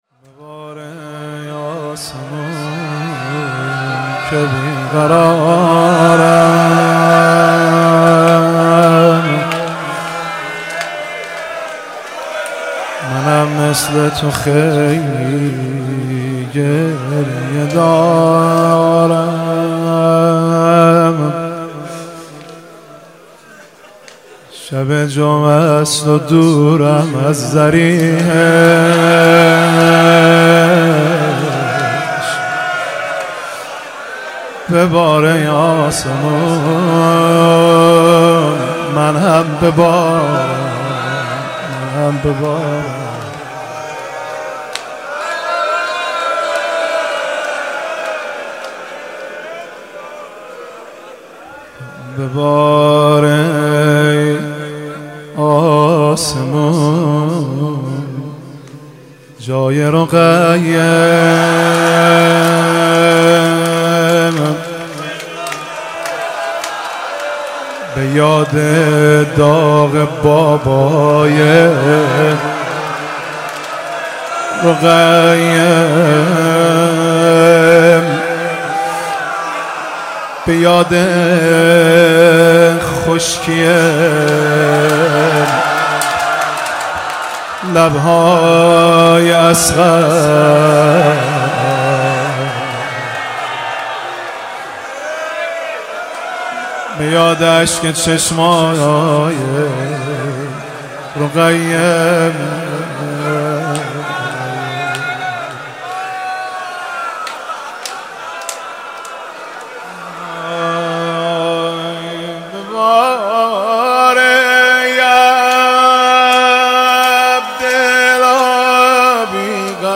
محفل عزاداری شب سوم محرم ۱۴۴۵ هیئت‌ثارالله‌زنجان
روضه پیش زمینه زمینه نوحه واحد آذری واحد عربی شور شور